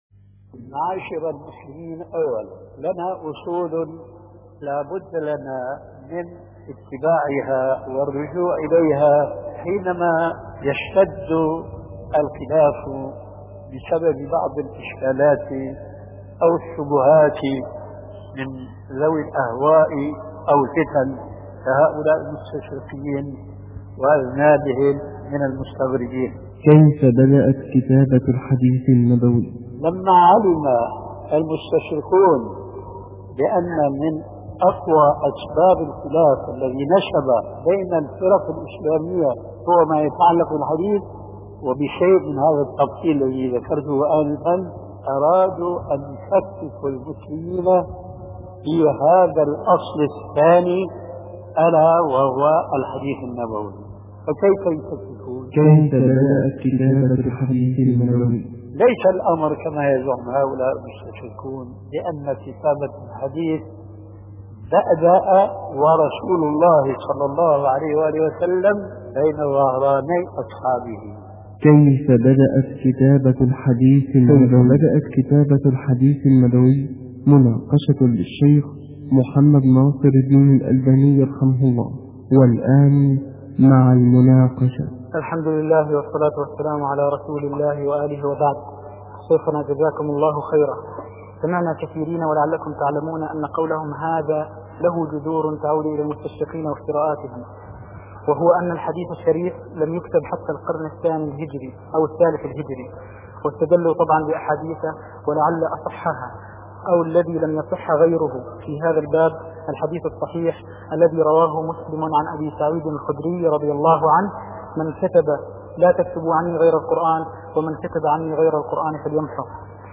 شبكة المعرفة الإسلامية | الدروس | كيف بدأت كتابة الحديث النبوي |محمد ناصر الدين الالباني